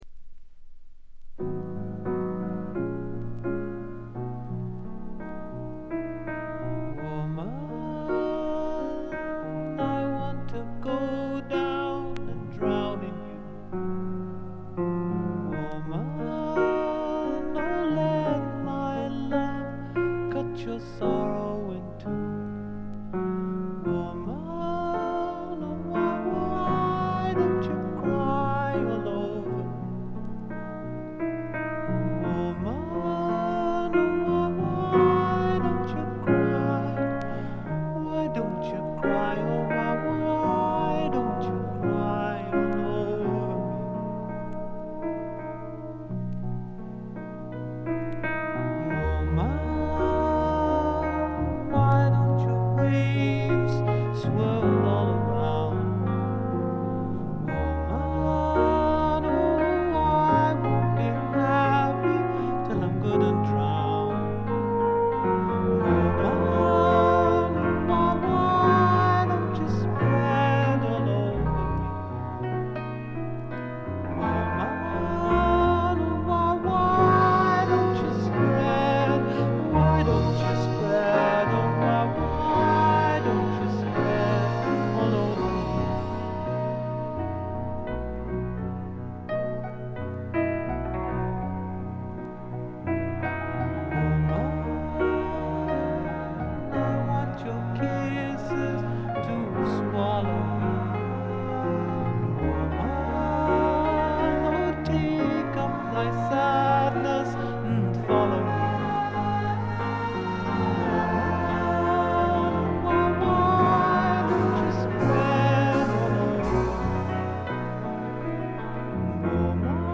A1冒頭でプツ音。
試聴曲は現品からの取り込み音源です。